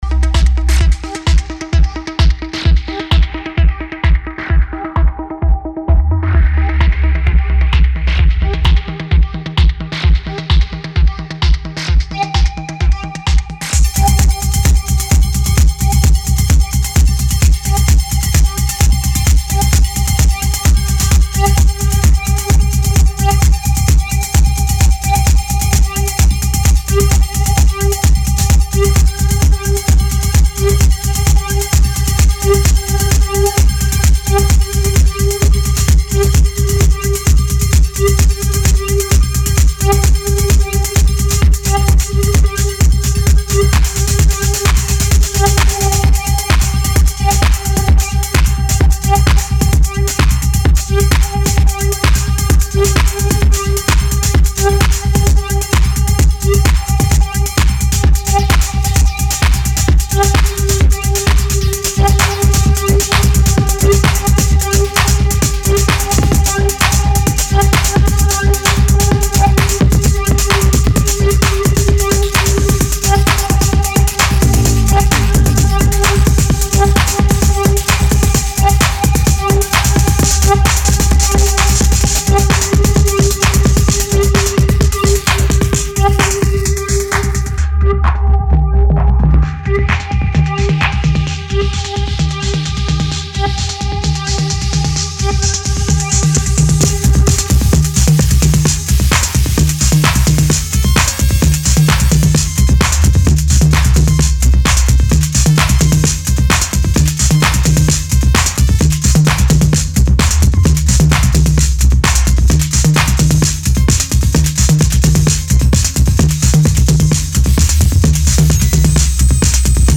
シカゴ・ゲットー・ハウスにフレンチ・エレクトロを合体、IDM/グリッチ的エディット感覚も注入された強刺激グルーヴ。